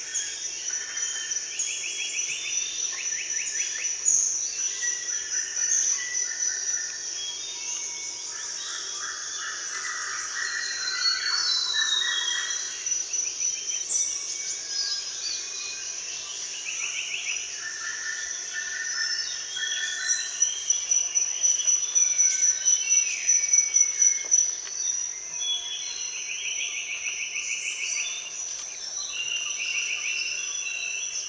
mellow-jungle-sounds
mellow-jungle-sounds.mp3